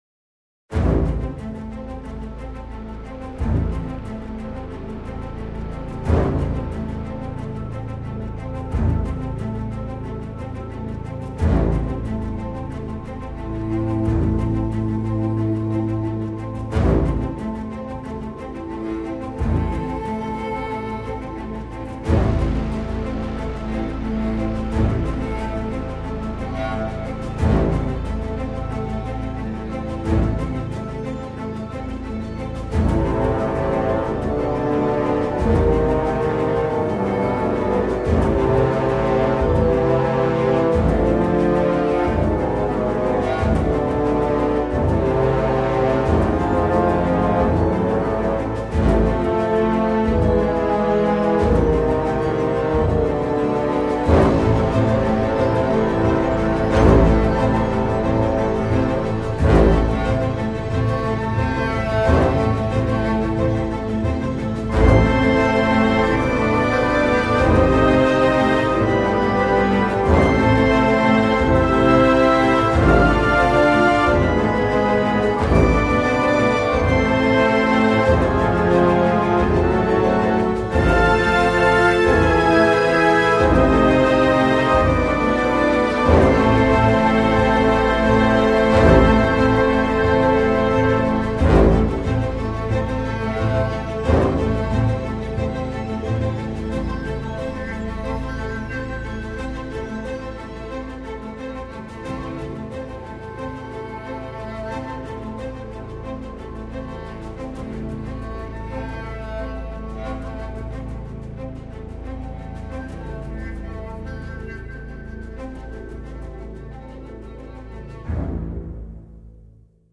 brass melody